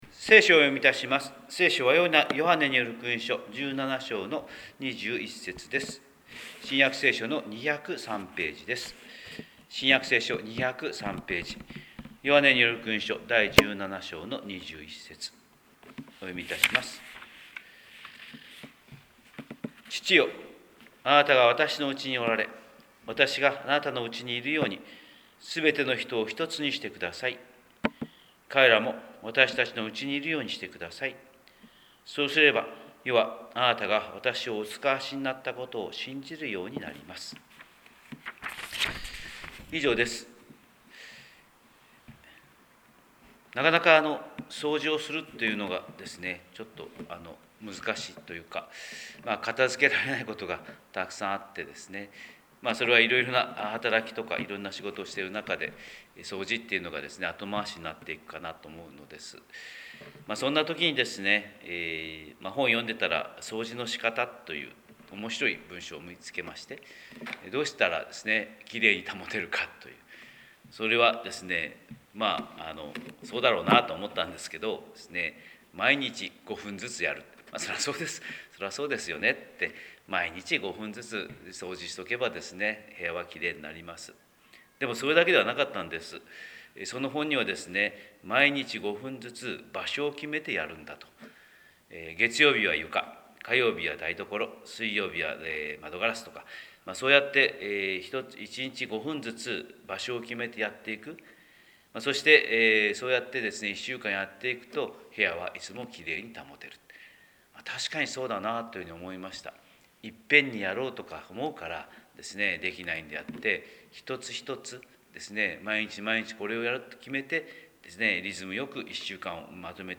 広島教会朝礼拝250527「ひとつに」